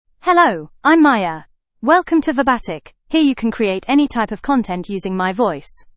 FemaleEnglish (United Kingdom)
Maya is a female AI voice for English (United Kingdom).
Voice sample
Maya delivers clear pronunciation with authentic United Kingdom English intonation, making your content sound professionally produced.